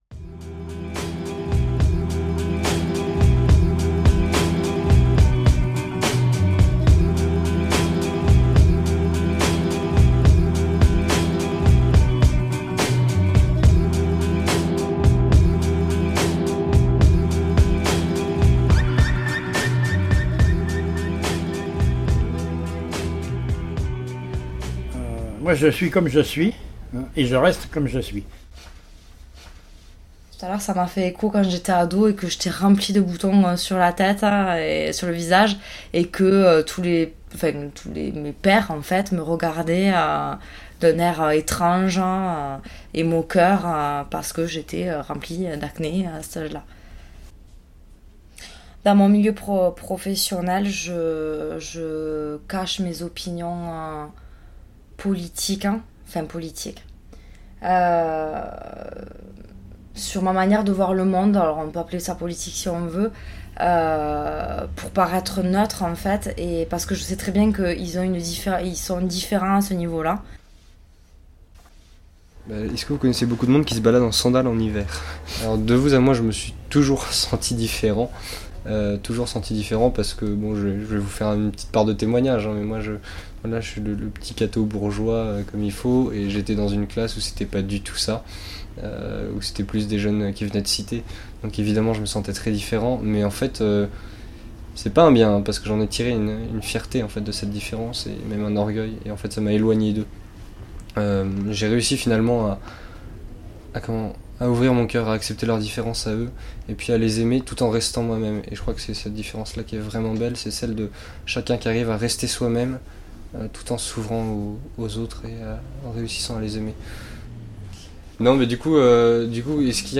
Résidence de Territoire à Pamiers (Ariège) – Janvier 2020
Les personnes qui ont franchi la porte du Bus ont eu également la possibilité de répondre à quelques questions que je leurs ai posées sur le thème de la différence. Comme un accompagnement à ces photographies, cette restitution sonore de 14 minutes témoigne de notre vision de « La différence » et de notre positionnement face aux autres.
montage-audio-pamiers-difference-v3.mp3